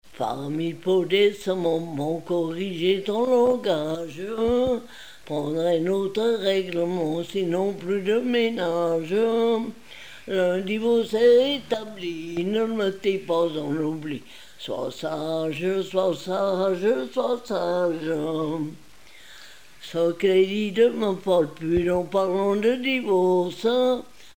Mieussy
Pièce musicale inédite